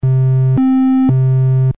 オシレータには６種類の波形が用意されている。
tri.mp3